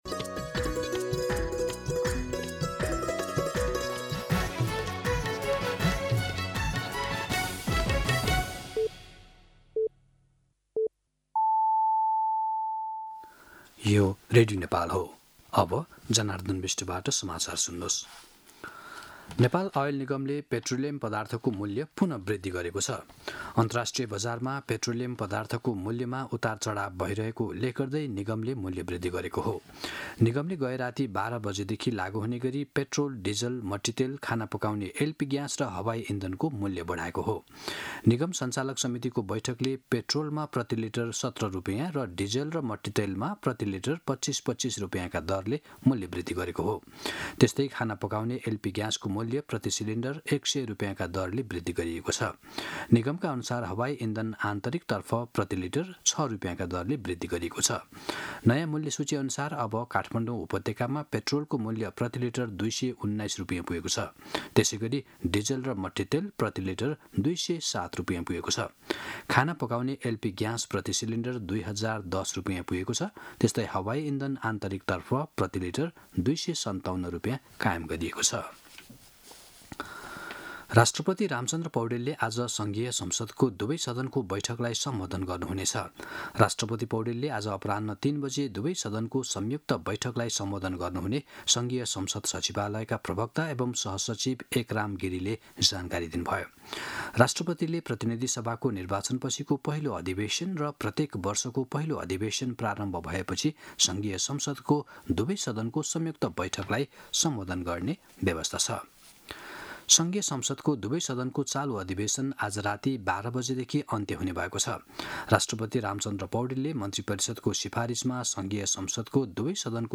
मध्यान्ह १२ बजेको नेपाली समाचार : २७ चैत , २०८२